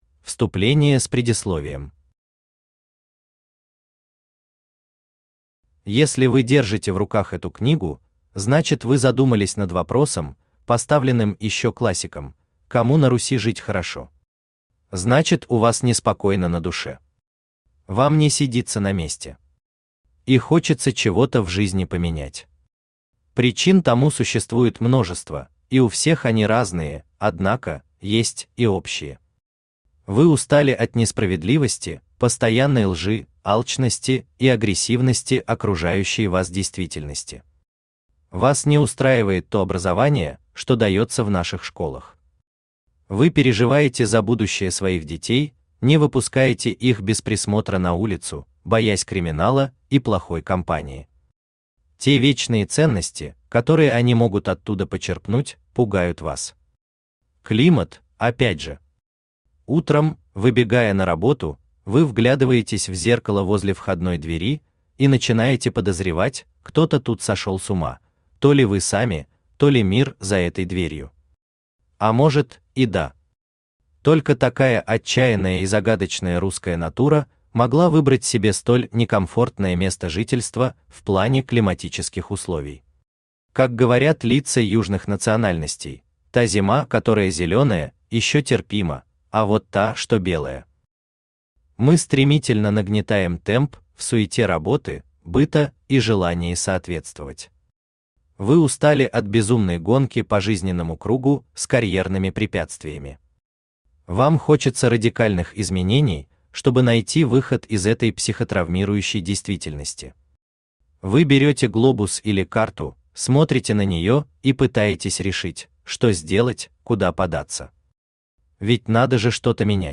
Аудиокнига Гоа. Инструкция для тех, кто устал жить по инструкциям | Библиотека аудиокниг
Инструкция для тех, кто устал жить по инструкциям Автор Игорь Станович Читает аудиокнигу Авточтец ЛитРес.